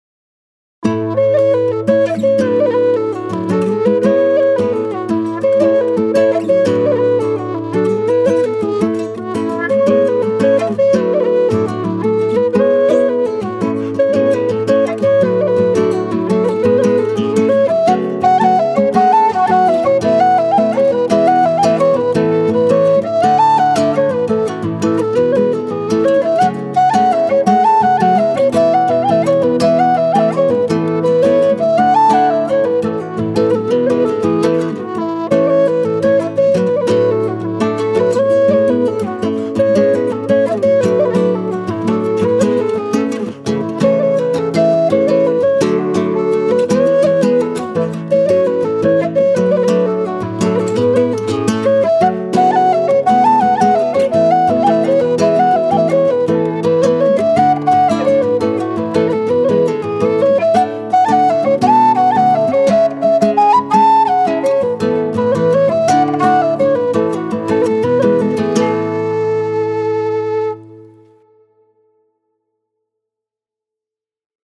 2_27 – 1ere jig irlandaise avec guitare (audio):
2_27 - 1ere jig irlandaise avec guitare.mp3